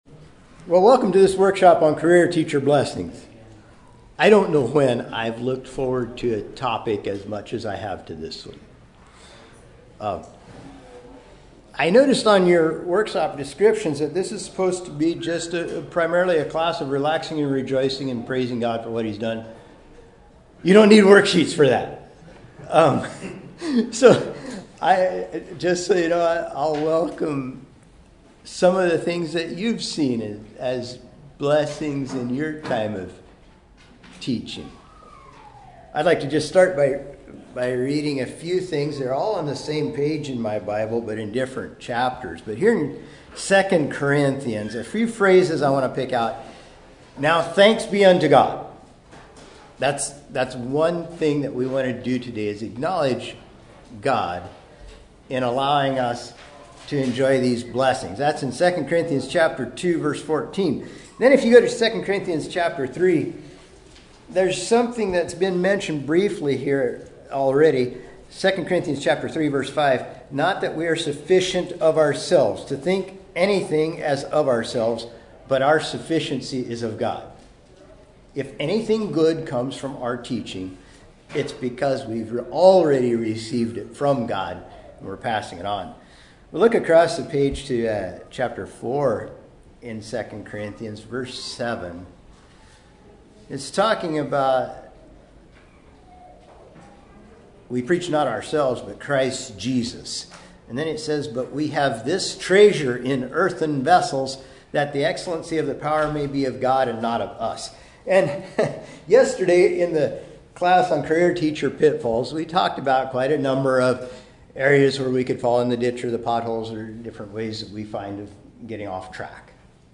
Home » Lectures » Career Teacher Blessings